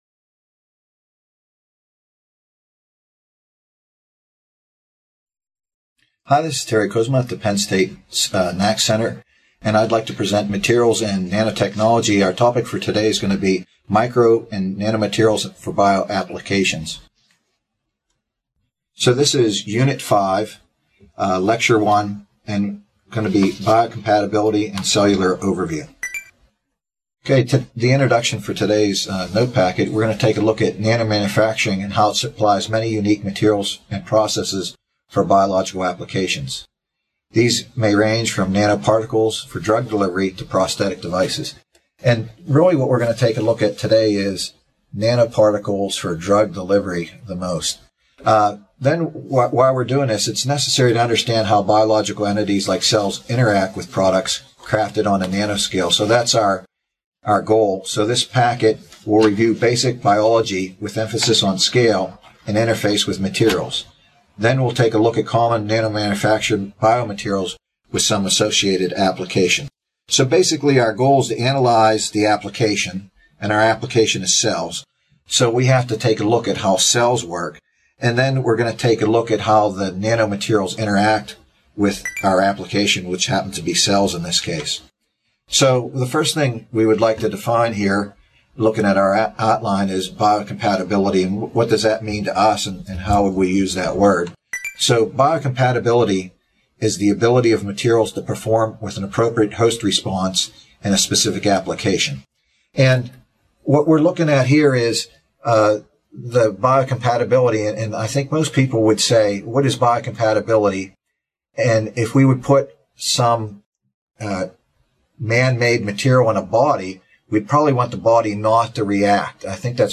This video, provided by the Nanotechnology Applications and Career Knowledge Support (NACK) Center at Pennsylvania State University, is part one of a four-part lecture on the interactions between biological entities and products crafted on a nanoscale, with a focus on nanoparticles for drug delivery.